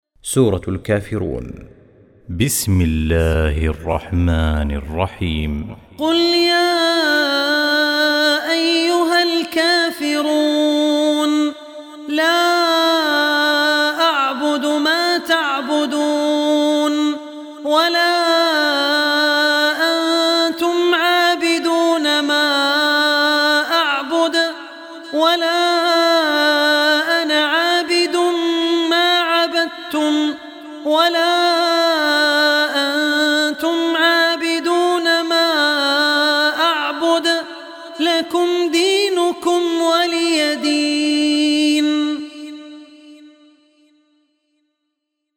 Surah Kafirun Recitation by Abdul Rehman Al Ossi
Surah Kafirun, listen online mp3 tilawat / recitation in the voice of Sheikh Abdur Rehman Al Ossi.